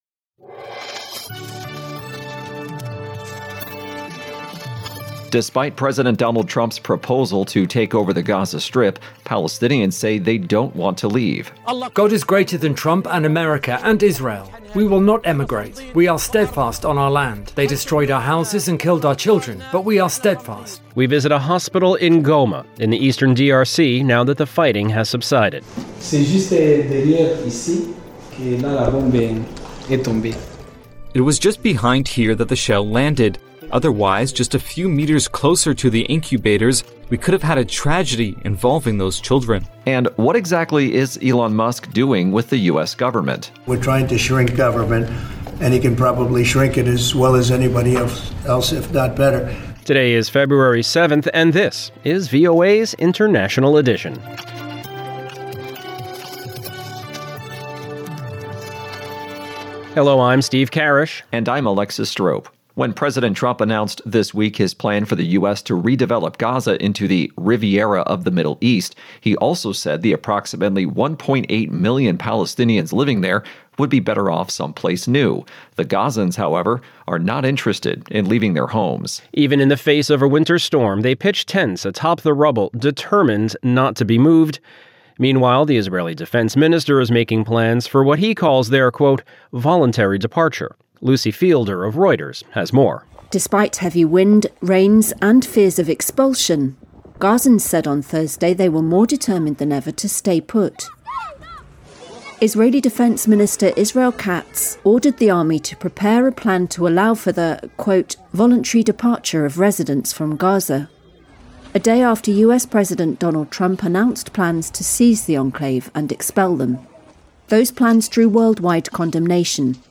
International Edition is VOA's premier global news podcast. Immerse yourself in the latest world events as we provide unparalleled insights through eye-witness accounts, correspondent reports, and expert analysis.